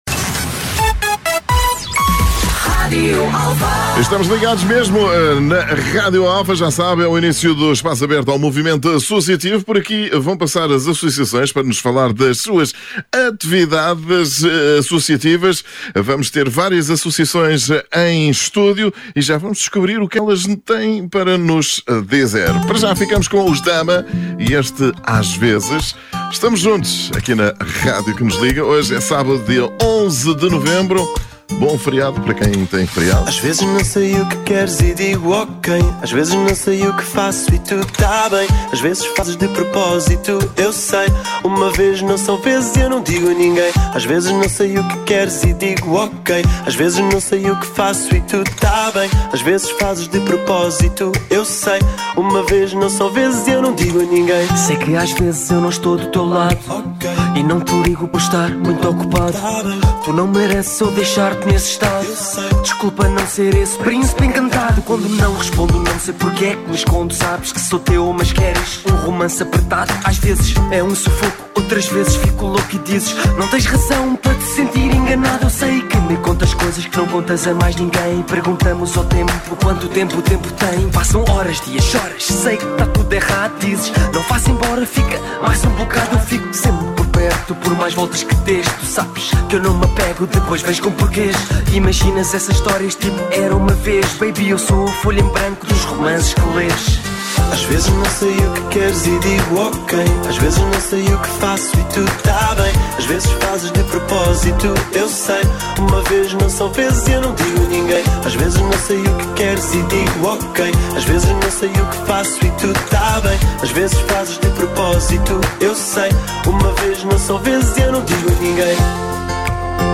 Todas as semanas a equipa do Espaço Aberto recebe em estúdio as associações lusófonas para a divulgação das atividades associativas.